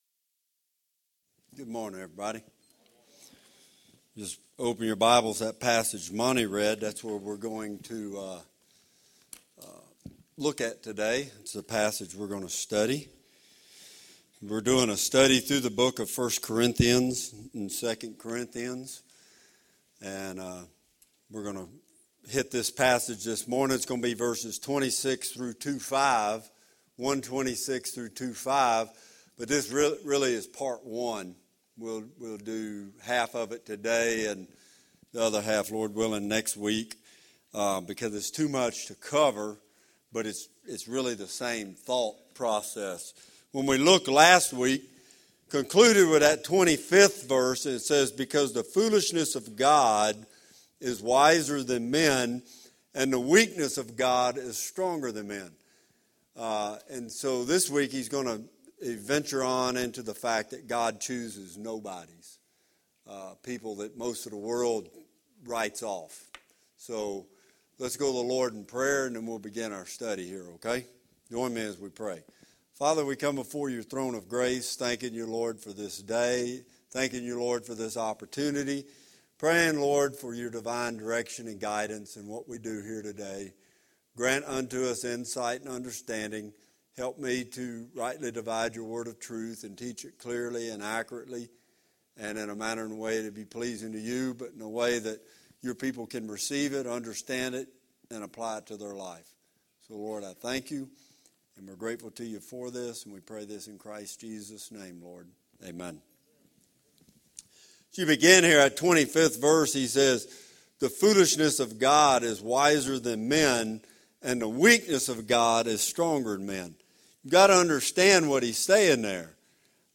Fellowship of Huntsville Church Sermon Archive